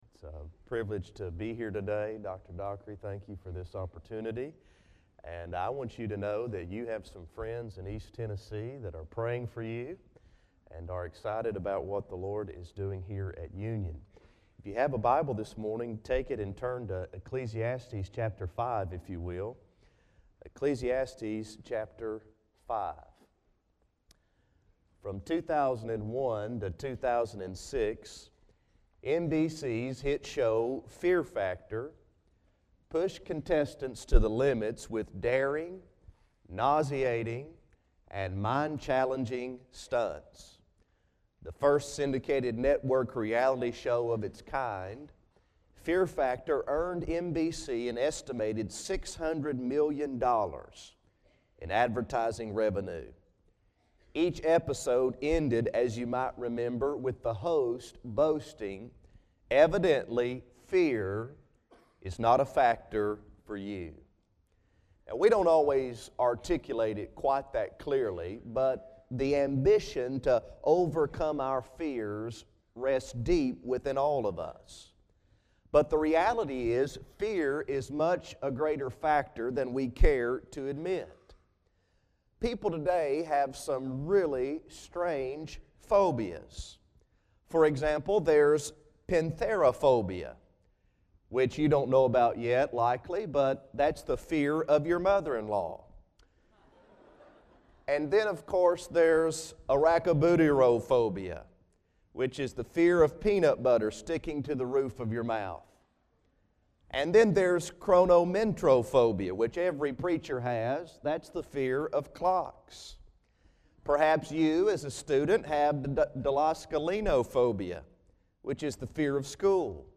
Chapel
Address: Know Fear from Ecclesiastes 5:1-7